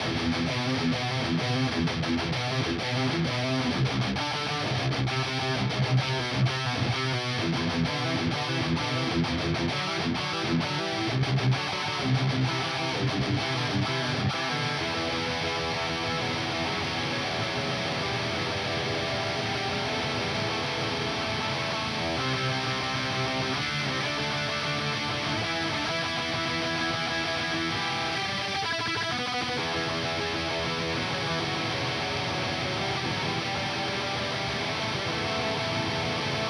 Das Fuzz-Pedal ist eigentlich auch toll. Klingt schön kaputt: Dein Browser kann diesen Sound nicht abspielen.